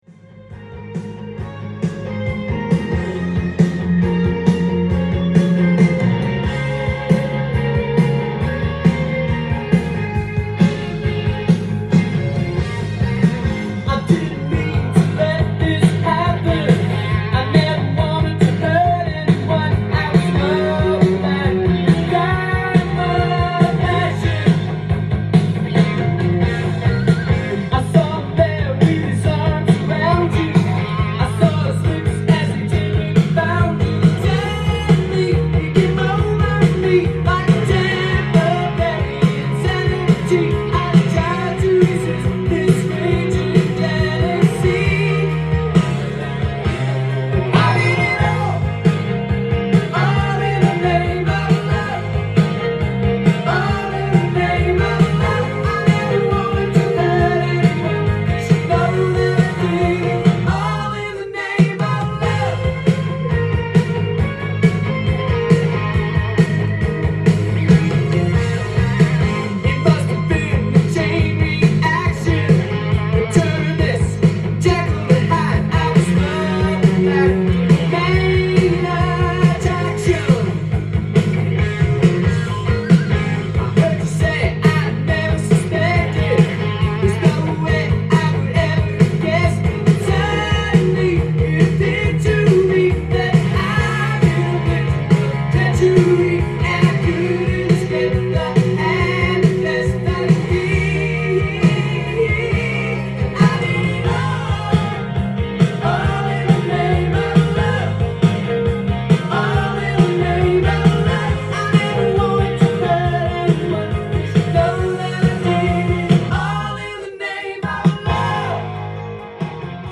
ジャンル：AOR
店頭で録音した音源の為、多少の外部音や音質の悪さはございますが、サンプルとしてご視聴ください。